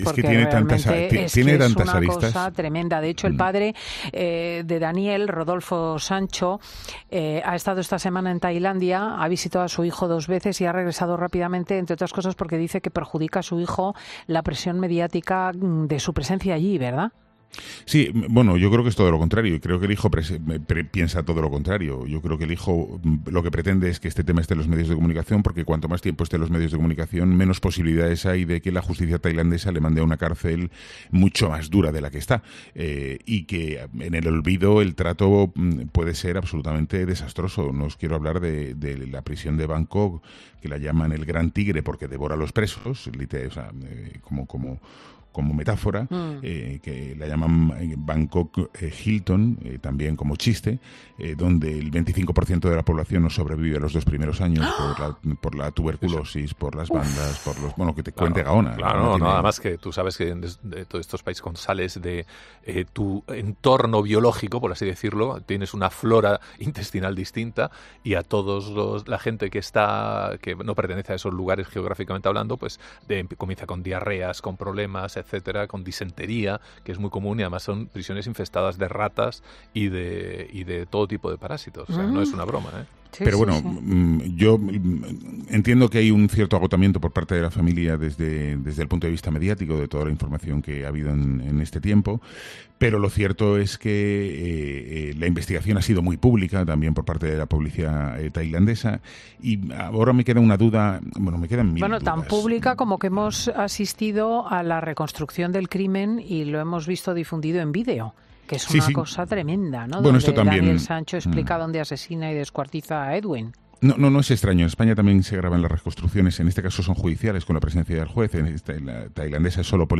Nacho Abad ha dado, en 'Fin de Semana', algunos detalles sobre la cárcel de Bangkok a la que conocen como 'El gran tigre'.